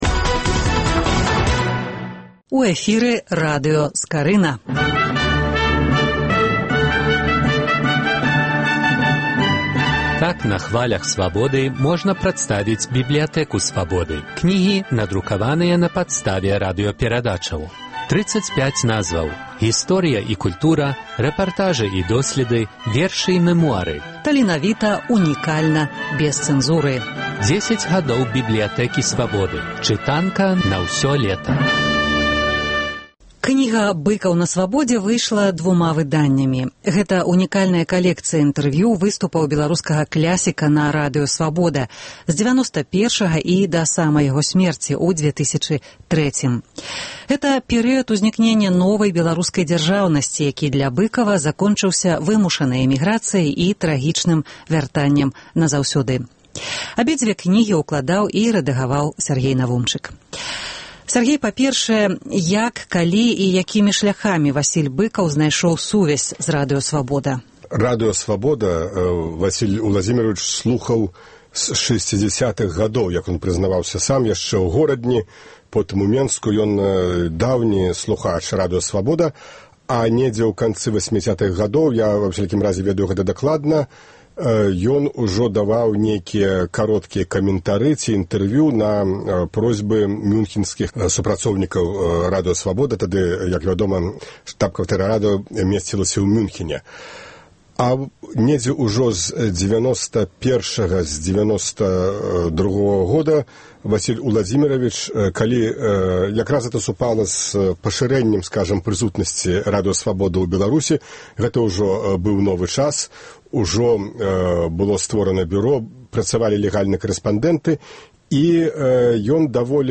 гутарка